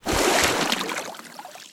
0335ec69c6 Divergent / mods / Soundscape Overhaul / gamedata / sounds / material / human / step / t_water4.ogg 58 KiB (Stored with Git LFS) Raw History
t_water4.ogg